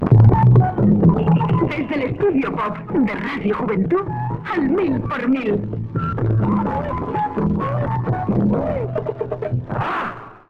Indicatiu del programa.
Musical
FM